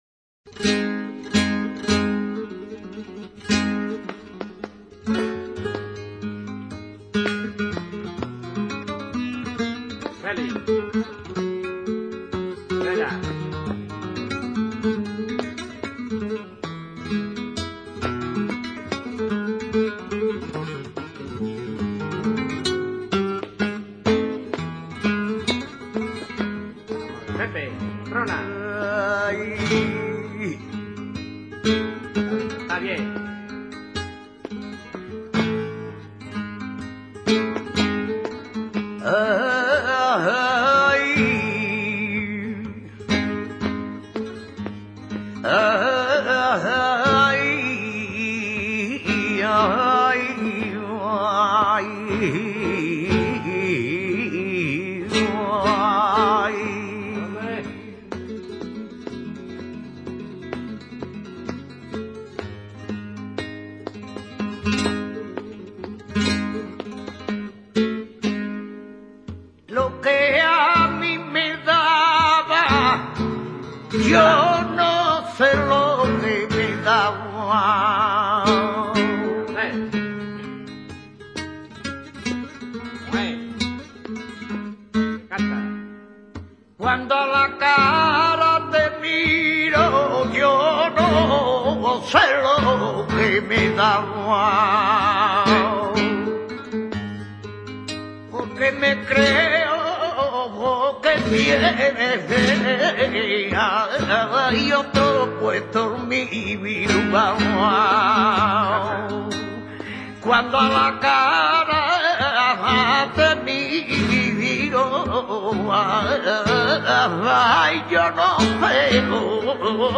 Sonidos y Palos del Flamenco
solea.mp3